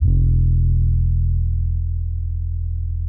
OZ - 808 5.wav